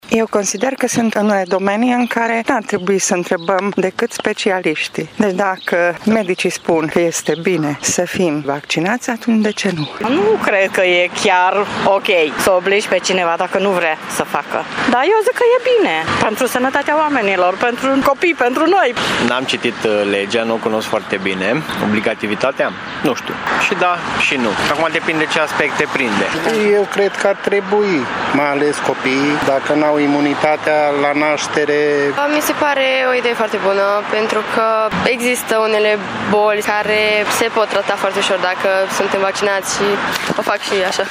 Unii târgumureșeni au rețineri privind termenul de ”obligație” la vaccinare, în schimb cred că aceasta este cea mai bună formă de protecție la boli: